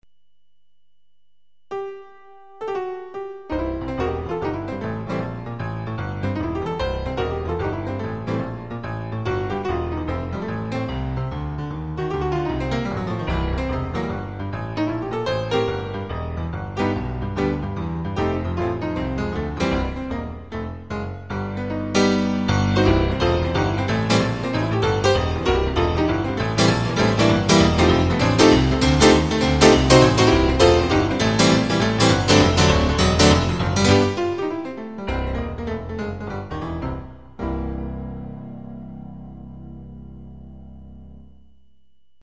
MP3 of me noodling on the keyboard.
boogie.mp3